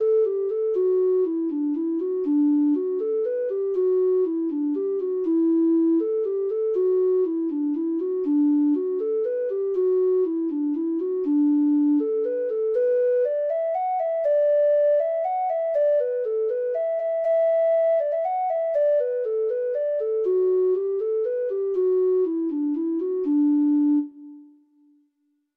Traditional Trad. MY OWN YOUNG DEAR (Irish Folk Song) (Ireland) Treble Clef Instrument version
Traditional Music of unknown author.
Irish